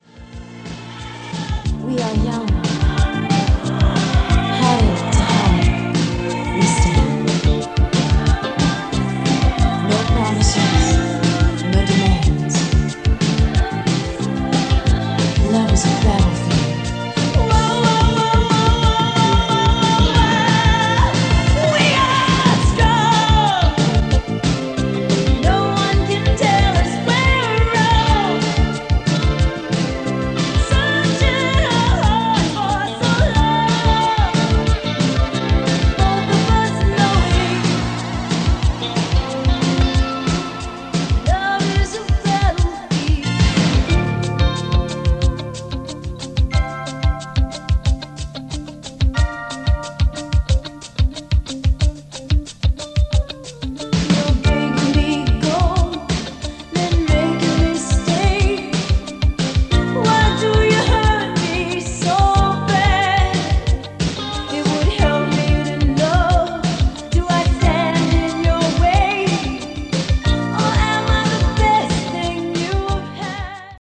Below is a test recording made with the KX200 and played back by it:
Type: 2-head, single compact cassette deck
Track System: 4-track, 2-channel stereo
Yamaha-KX-200-Test-Recording.mp3